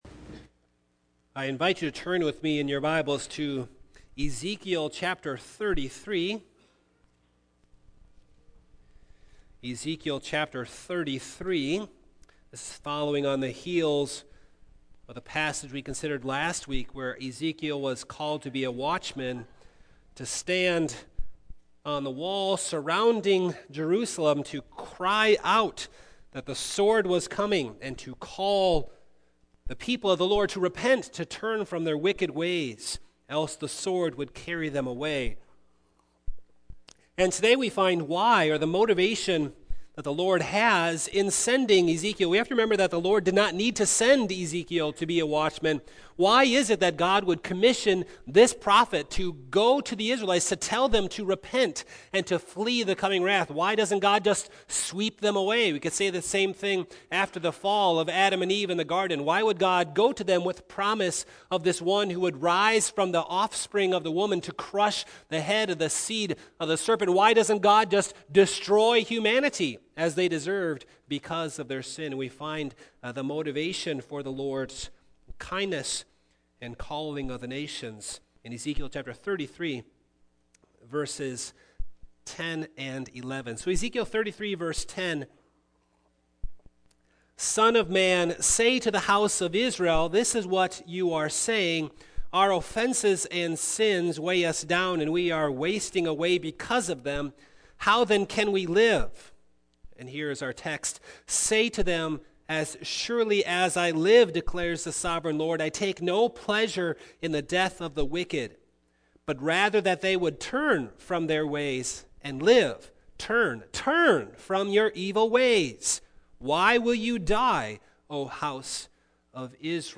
Single Sermons Passage